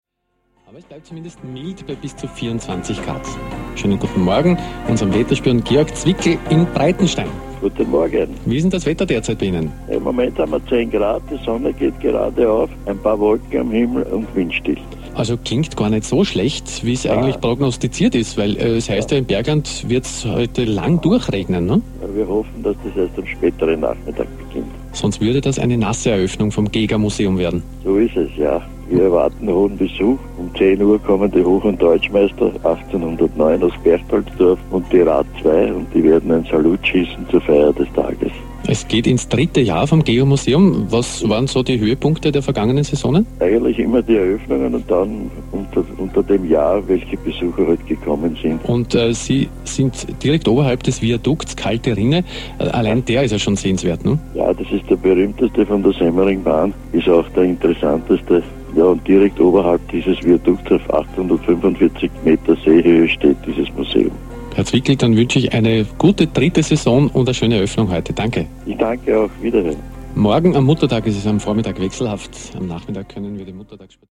Schon in aller Früh beim Radiointerview von Radio NÖ "